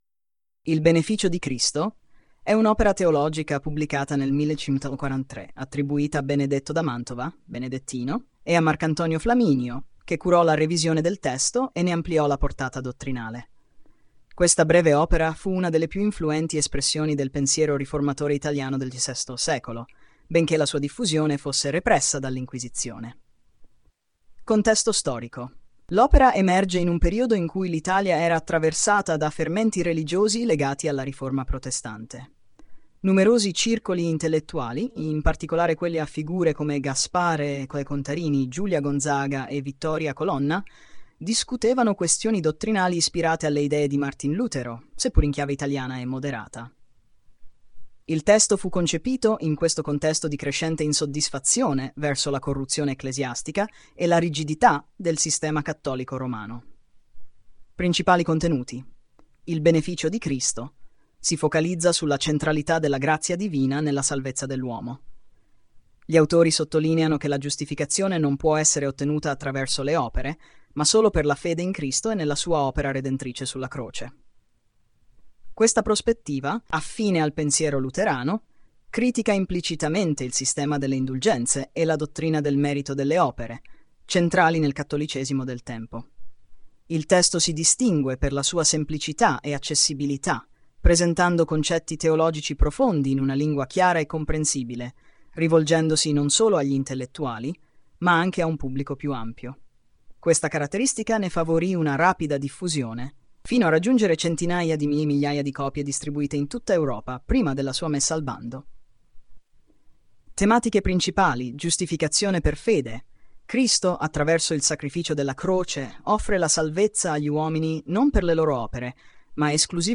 Lettura del testo corrispondente della Teopedia